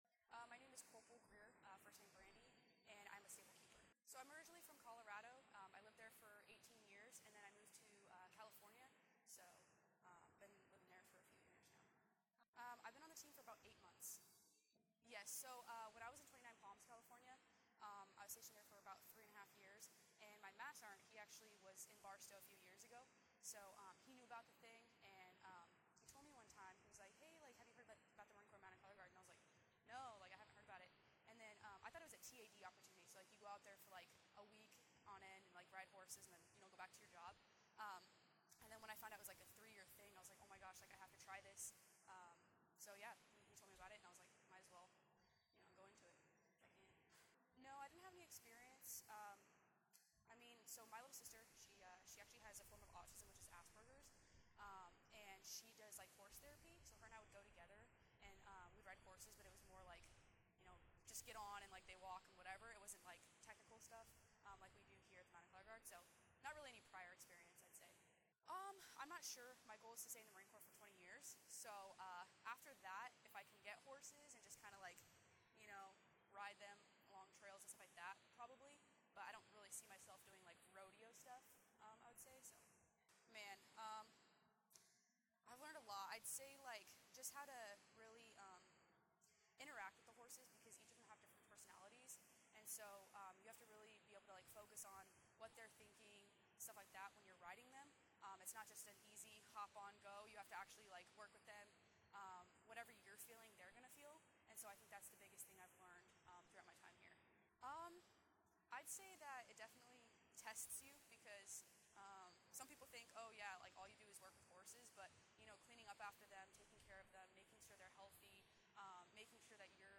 Audio Interview
conducts an interview at Iron Hill Ranch in Union, Missouri on May 13